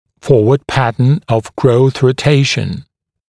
[‘fɔːwəd ‘pæt(ə)n əv grəuθ rə’teɪʃ(ə)n][‘фо:уэд ‘пэт(э)н ов гроус рэ’тэйш(э)н]горизонтальный тип роста челюстей (передняя ротация)